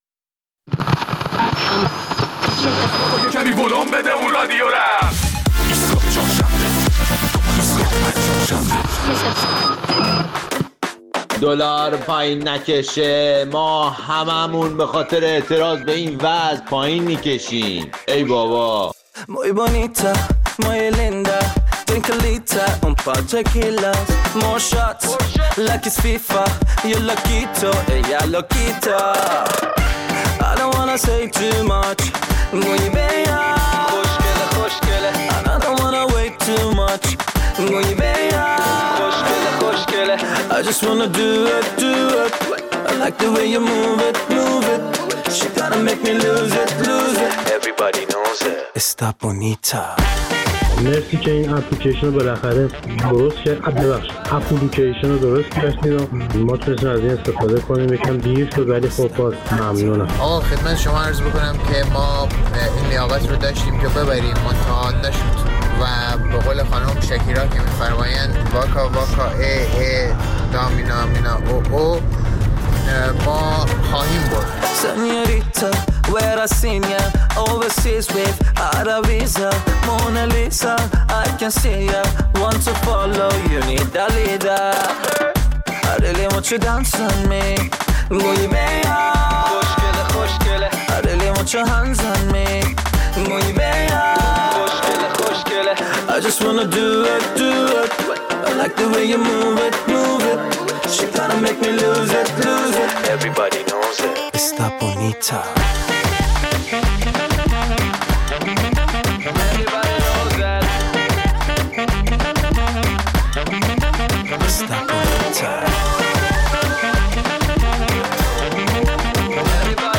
در این برنامه نظرات و مشاهدات شنوندگان را در مورد اعتصابات و اعتراضات هفته اخیر کسبه در بازارهای تهران و شهرهای مختلف کشور می‌شنویم.